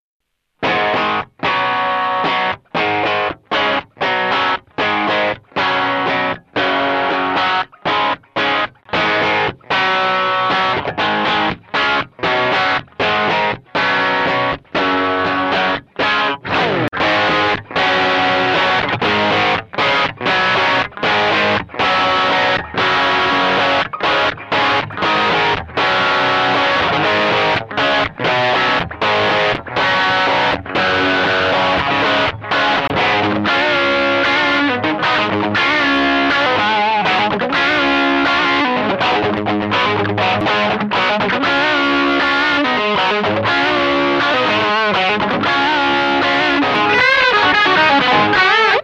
Die Gitarre klingt am Steg nun tatsächlich transparenter und dynamischer und ich bin gespannt, wie sich morgen bei der Probe am Petersburg-Marshall-Klon macht.
Ich habe mal ein Riff vorher und nachher aufgenommen, falls es interessiert (wobei ich finde, dass der Unterschied - wie so oft - im Spielgefühl dramatischer ist als das, was man hier hört).
3. Beide Pickups im Wechsel (links der Angus, rechts der JB)